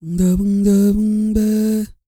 E-CROON 3003.wav